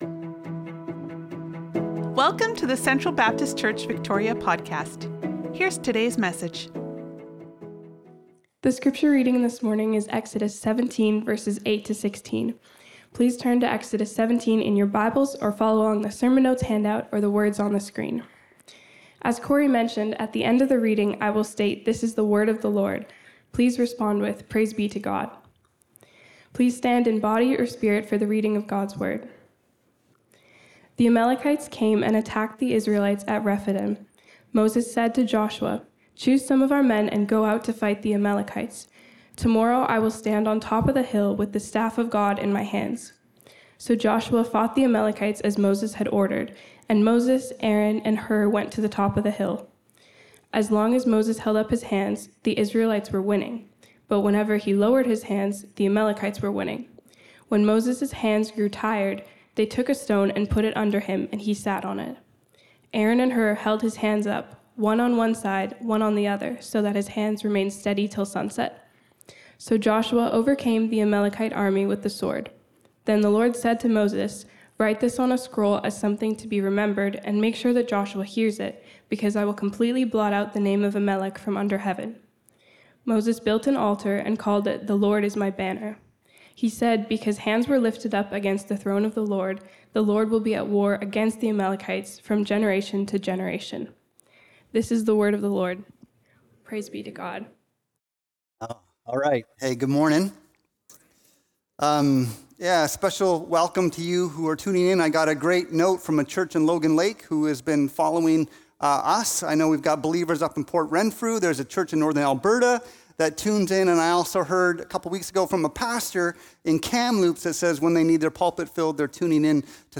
Sermons | Central Baptist Church